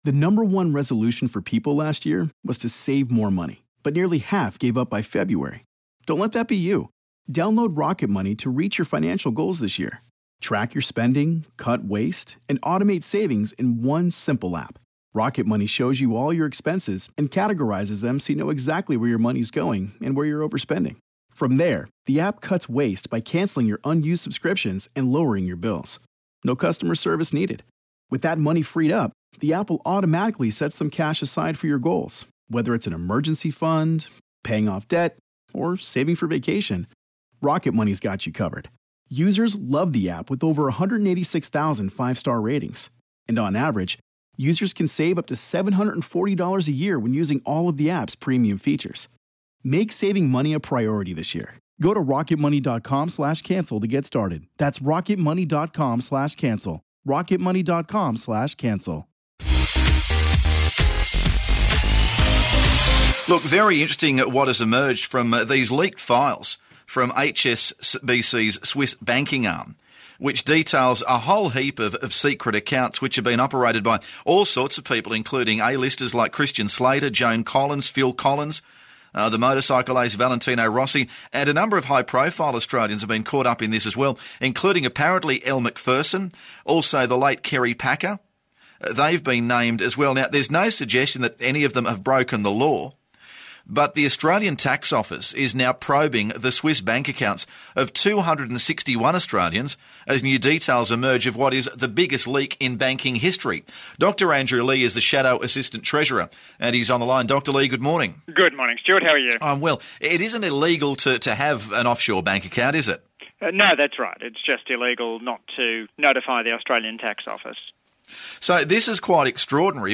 Shadow Assistant Treasurer Dr Andrew Leigh on the move.